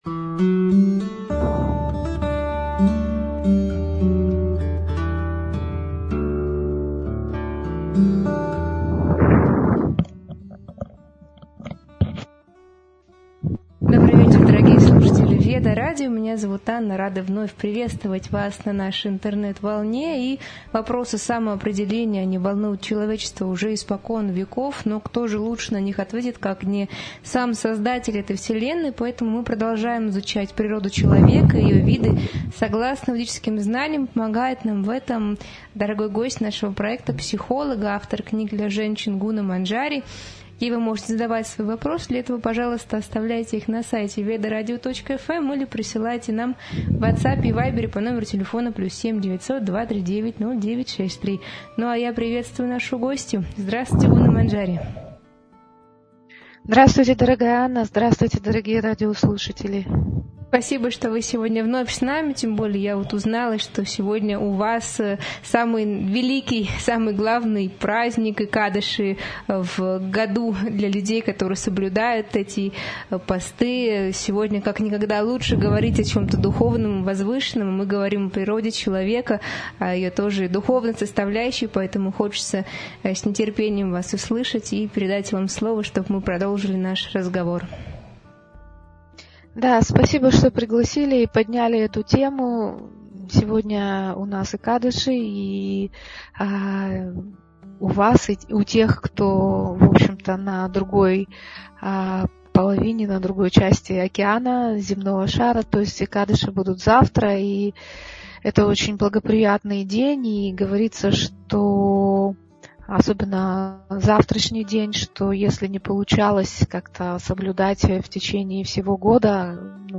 Эфир посвящён природе брахманов: их качествам, честности, смелости, способности доносить истину и сохранять духовную чистоту. Обсуждается влияние общения, образования и среды на сознание, важность понимания своей природы в Кали-югу, отличие знания от мудрости и роль духовной практики в развитии личности.